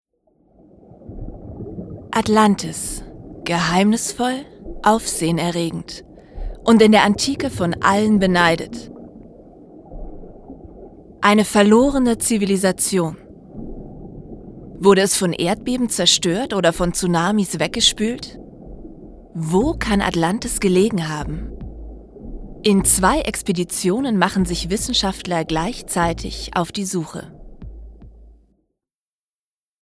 Mein Equipment: Rode T-1000.
Doku: Atlantis
9_Doku_Atlantis.mp3